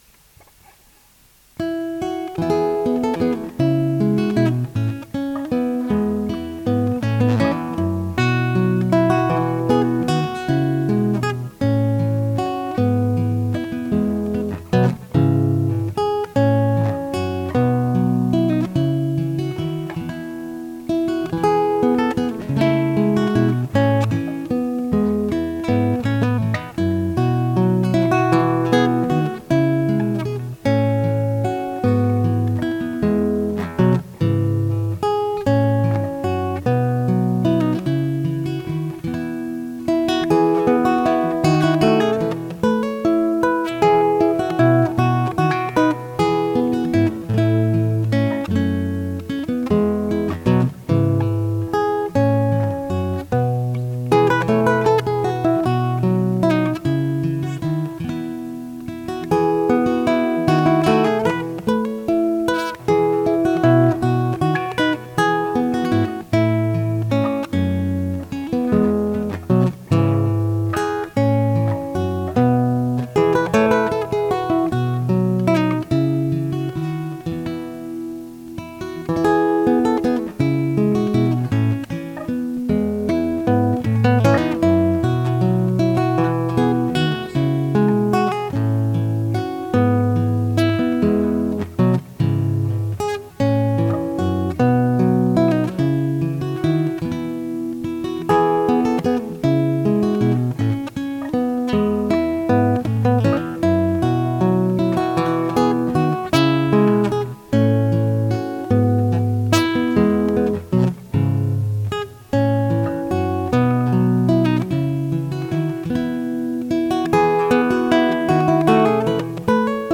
Guitar music
Thank you for the melodious tune and footnotes that added so much.